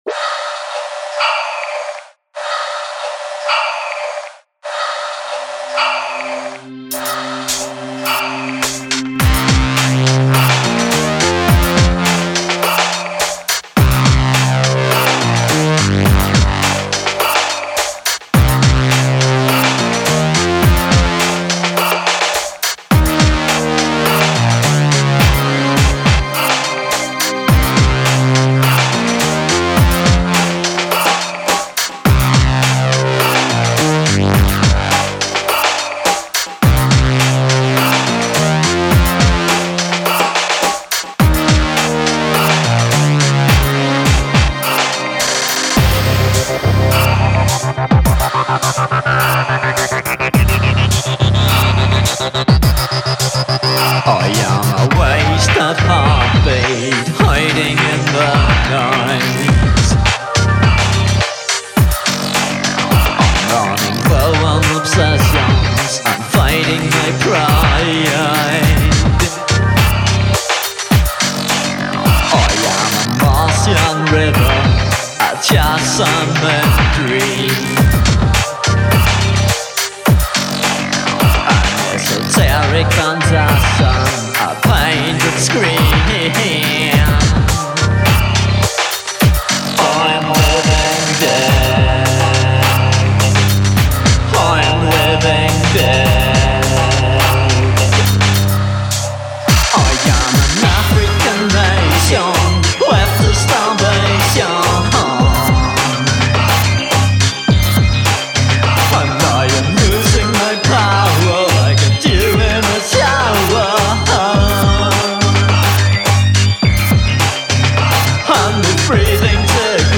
all instruments and vocals